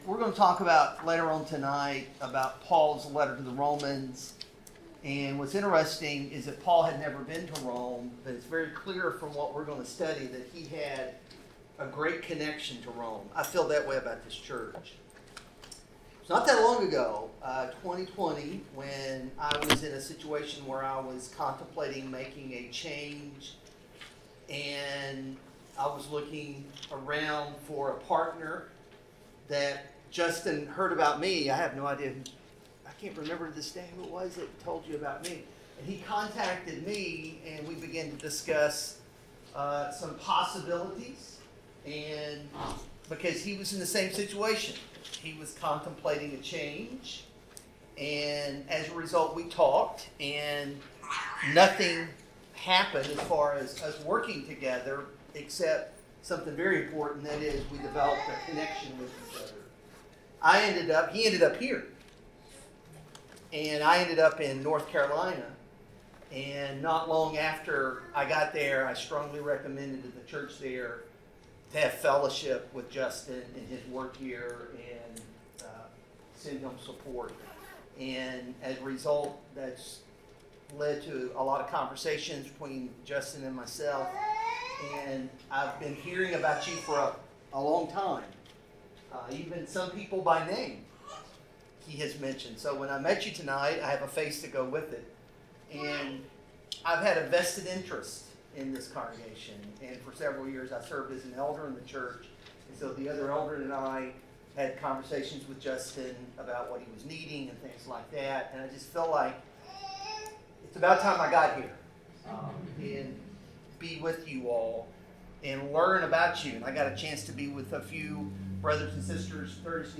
Passage: Philippians 1:3-2:16; Acts 16 Service Type: Sermon